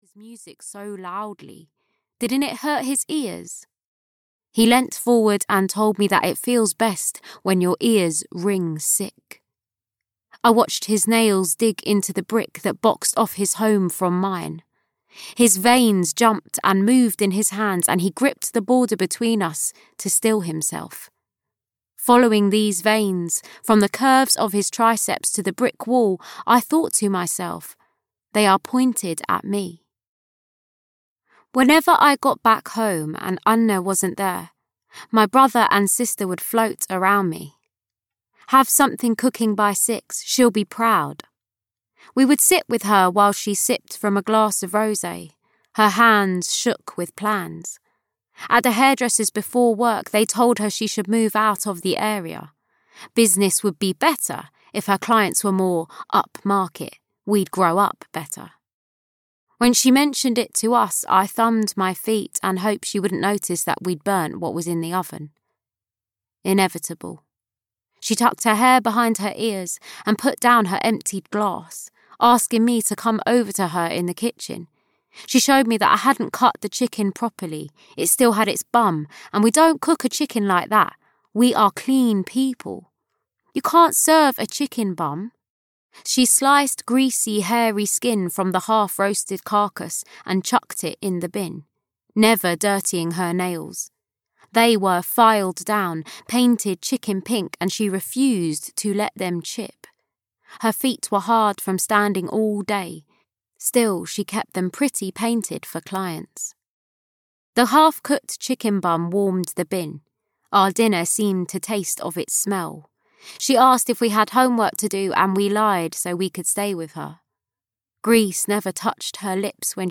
Keeping the House (EN) audiokniha
Ukázka z knihy